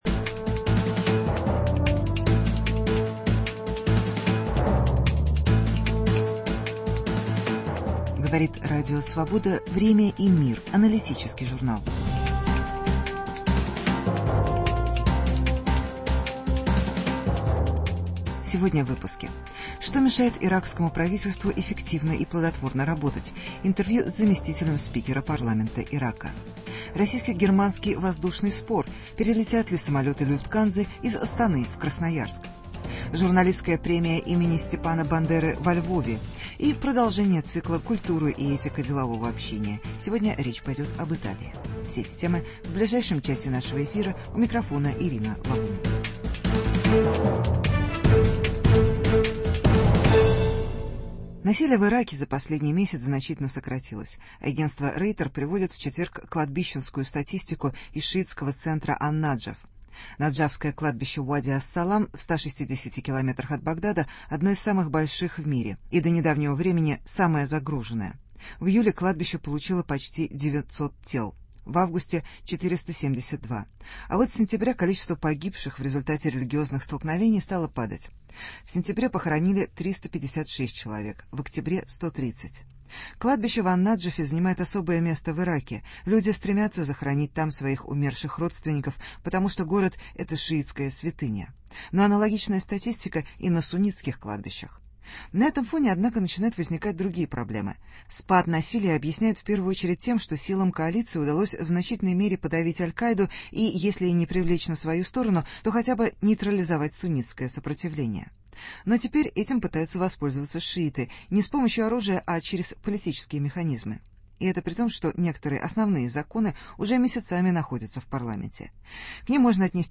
Интервью с членом иракского парламента.